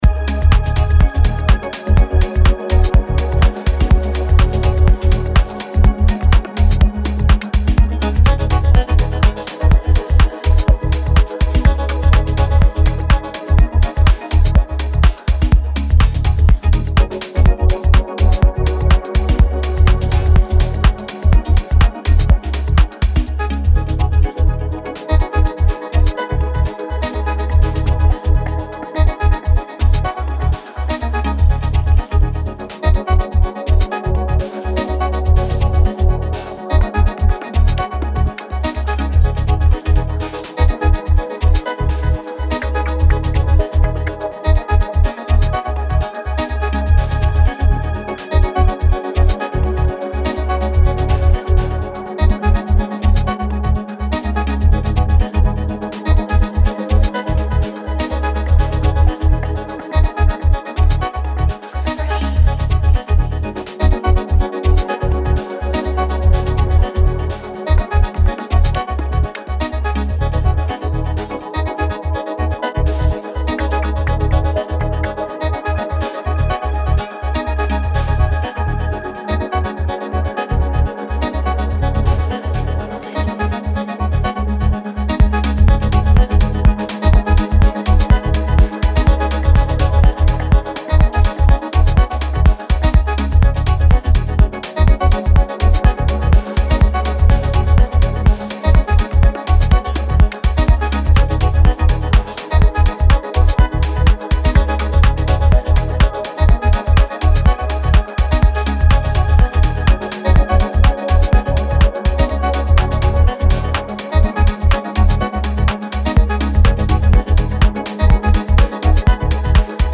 3 original tracks in different moods for the dancefloor